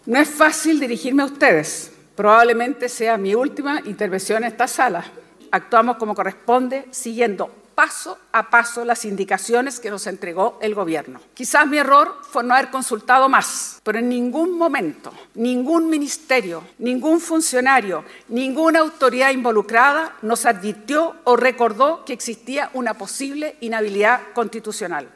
“Es uno de los momentos más duros y difíciles de mi vida. Me resulta más difícil la manera tan abrupta en la que terminan más de treinta años de servicio a mi país”, planteó en un tono pausado .